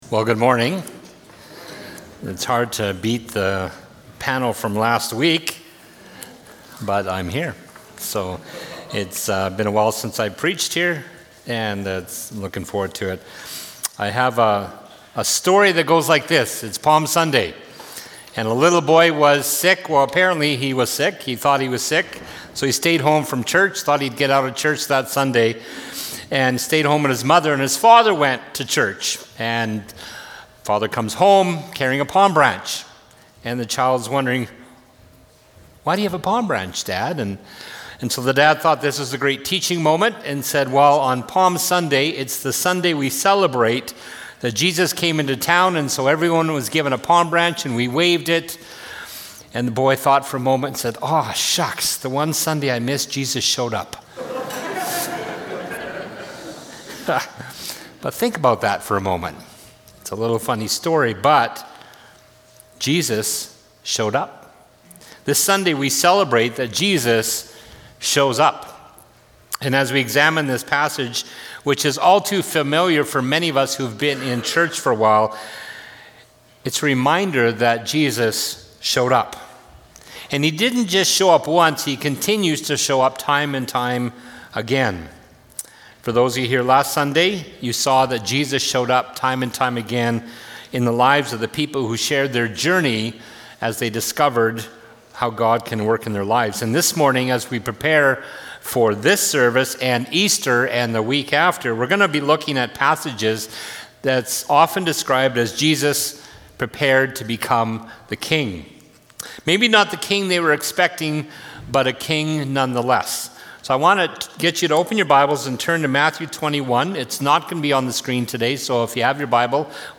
Sermons | Mount Olive Church